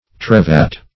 Search Result for " trevat" : The Collaborative International Dictionary of English v.0.48: Trevat \Tre"vat\, n. A weaver's cutting instrument; for severing the loops of the pile threads of velvet.